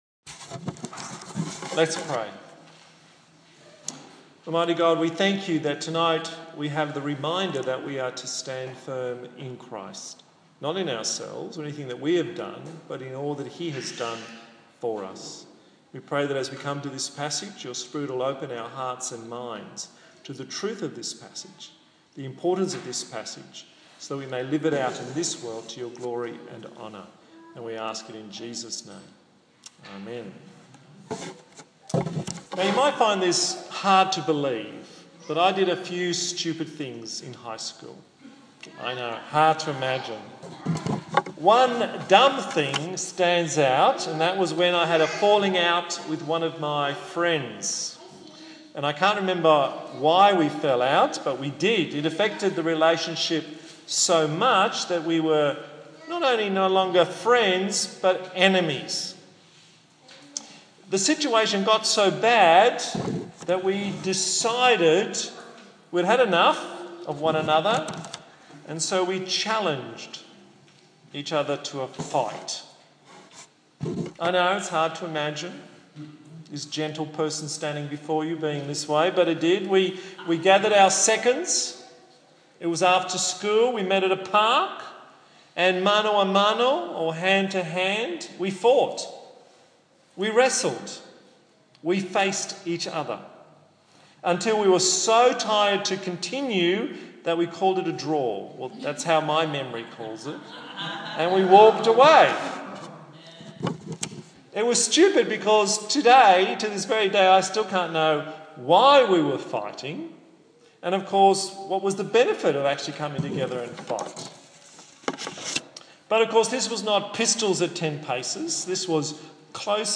27/12/2015 Ready for Battle Preacher